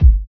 Kick (Accordion).wav